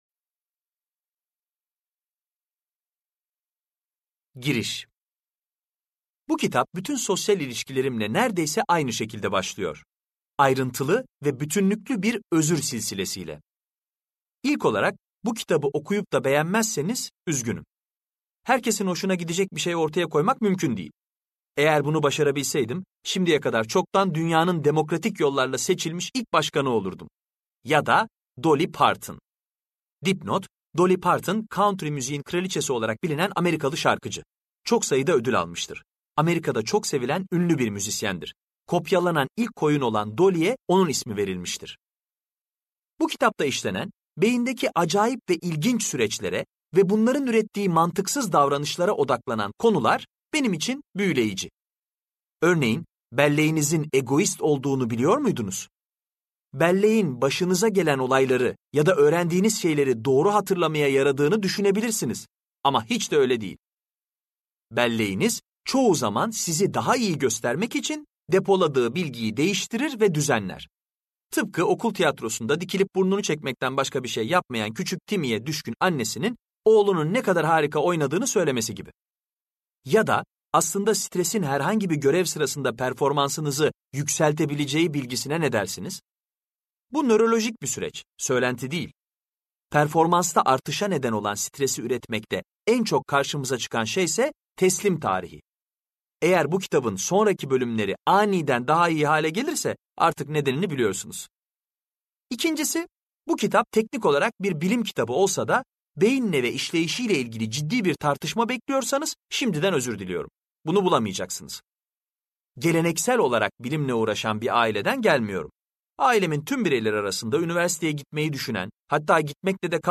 Seslendiren